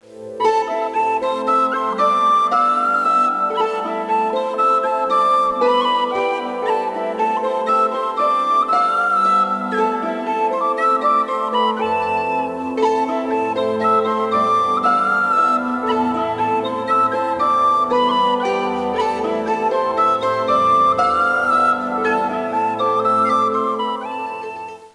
Irish Music
flute
flute.wav